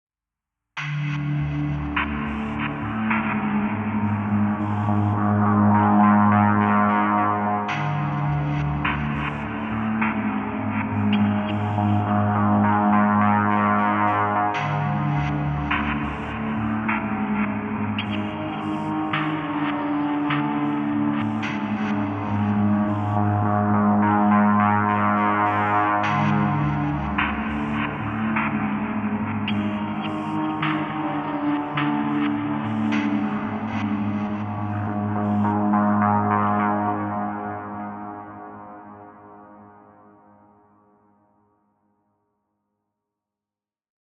LoFi Version: